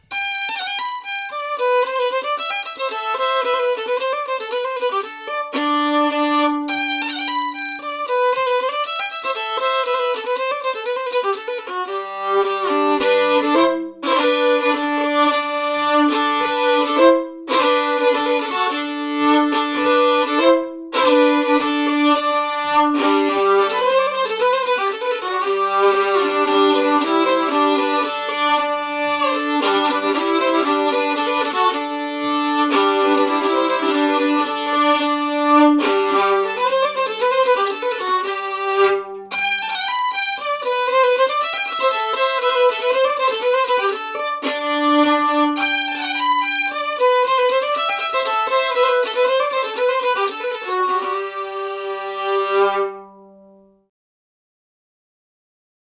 компенсируется скрипкой и клавишами, что, по правде говоря,